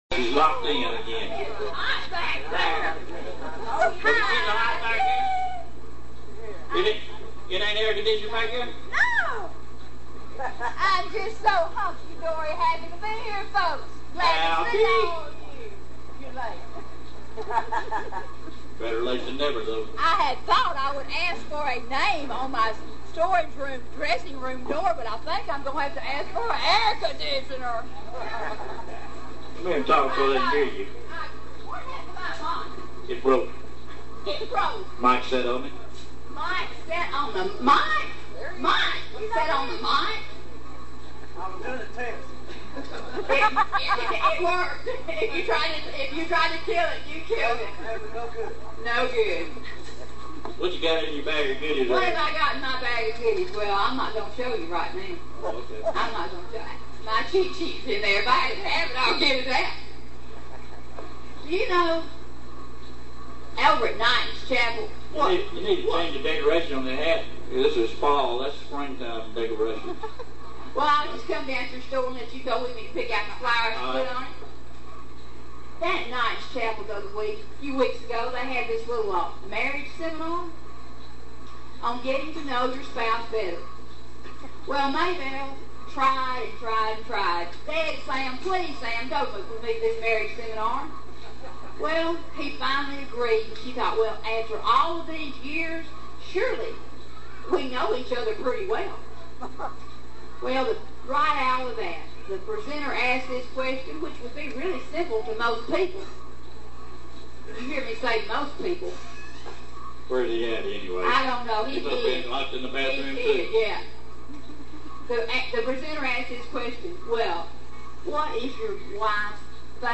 VFD Firehouse Jamboree Story!